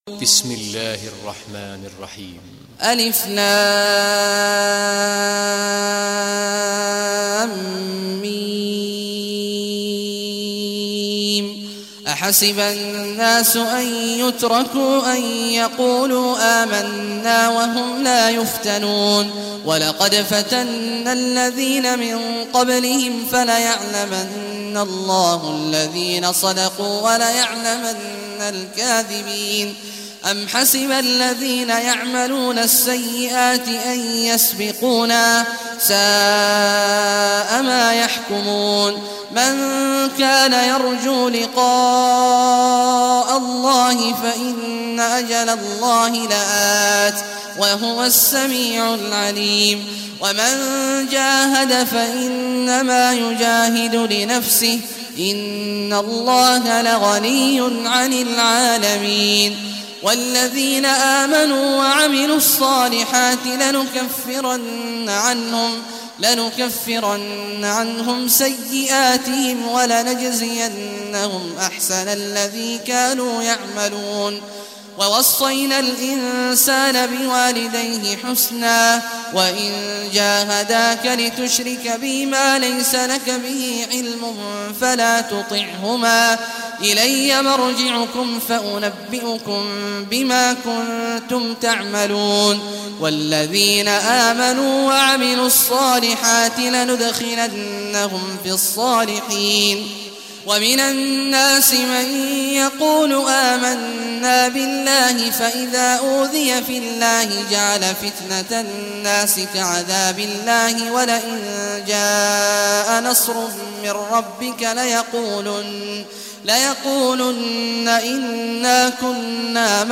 Surah Al-Ankabut Recitation Sheikh Al Juhany
Surah Al-Ankabut, listen or play online mp3 tilawat / recitation in Arabic in the beautiful voic of Sheikh Abdulah awad al Juhany.